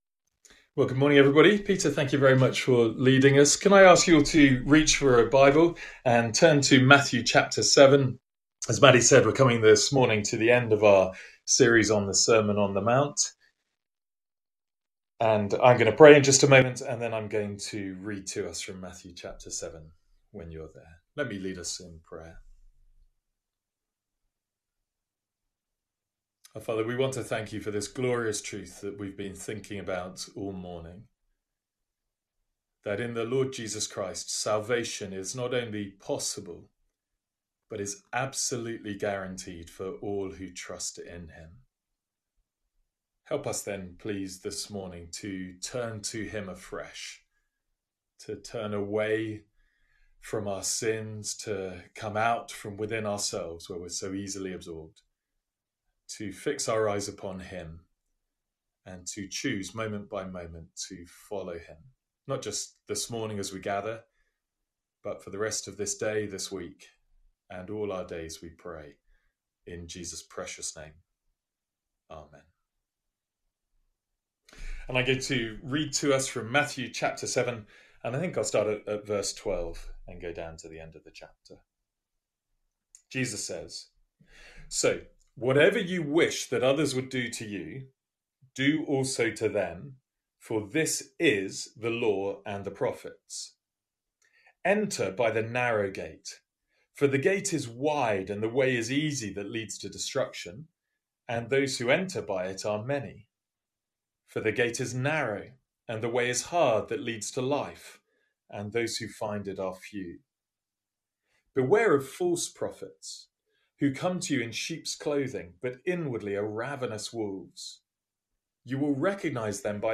Sermons | St Andrews Free Church
From our morning series in the Sermon on the Mount.